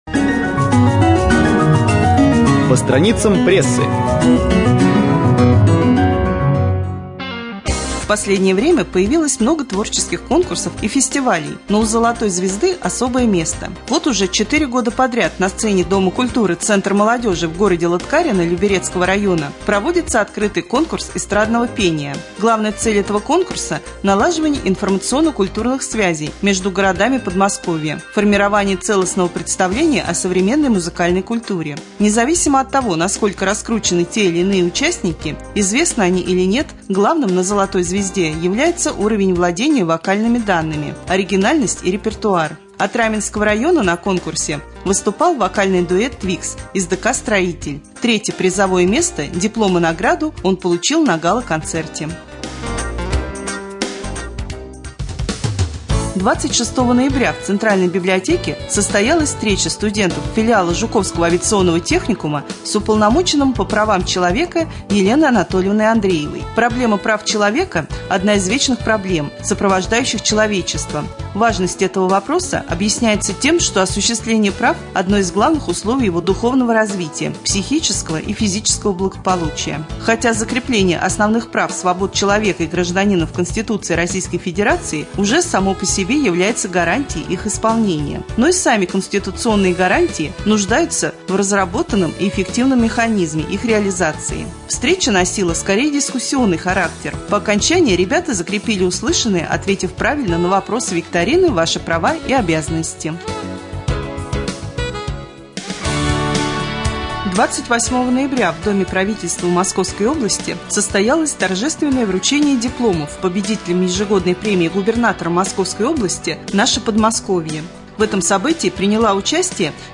1.Рубрика «По страницам прессы». Новости читает